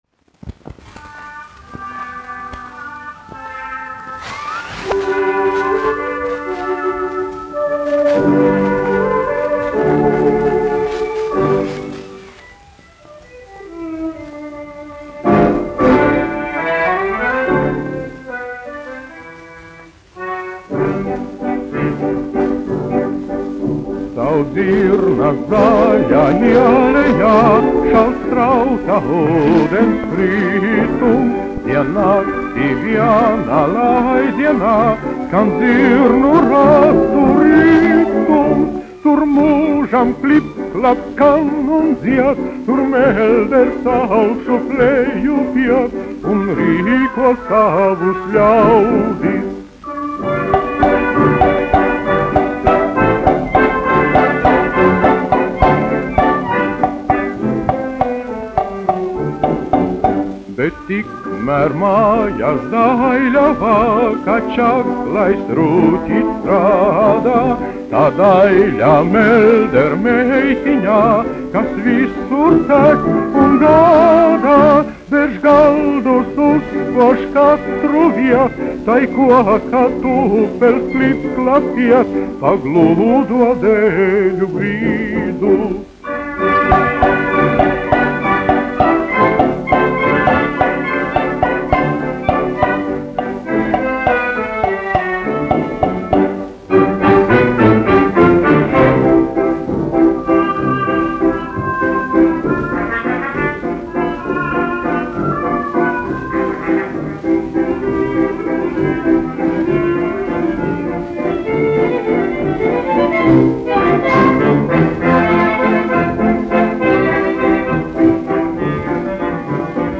1 skpl. : analogs, 78 apgr/min, mono ; 25 cm
Populārā mūzika -- Latvija
Skaņuplate